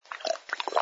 sfx_slurp_glass05.wav